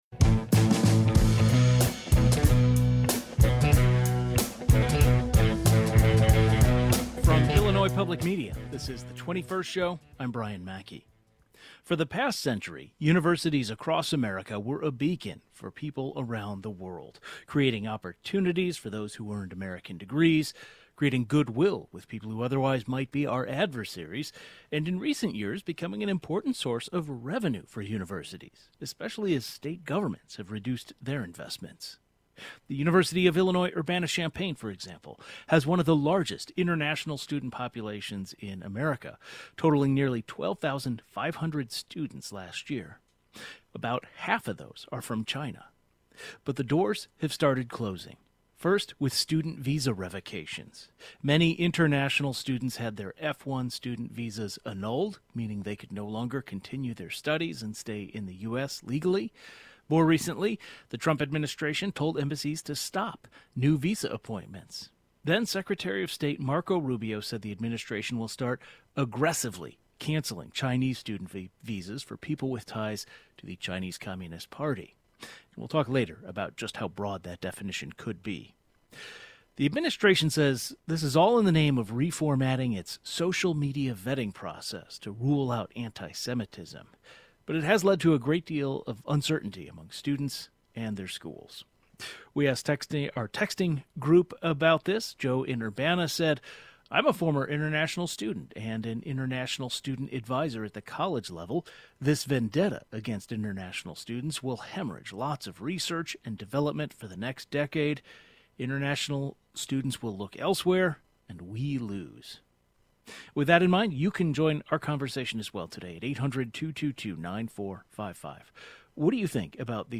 The Trump administration's new policies surrounding international students is creating uncertainty and anxiety among both students and universities. A legal expert on immigrants' rights, a journalist covering higher education, and a Chinese student who formerly attended the University of Illinois join the conversation.